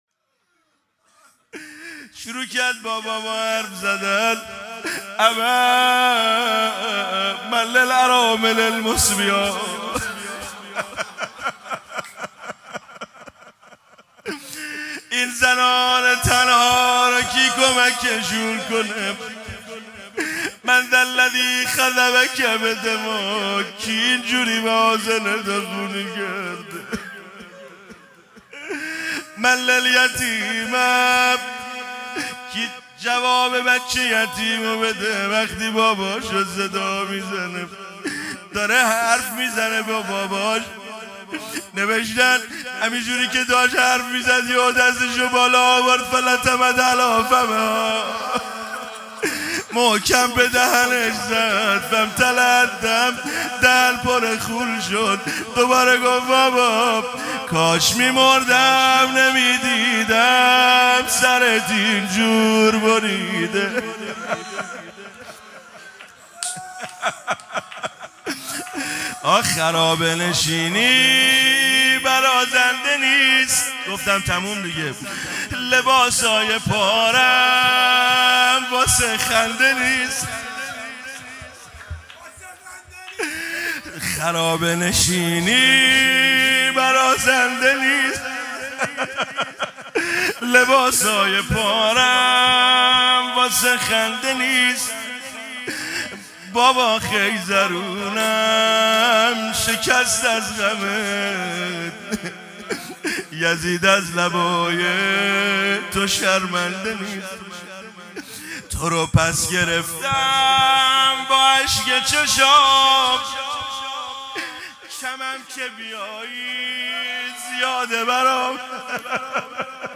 مراسم شب سوم عزاداری دهه اول محرم 1445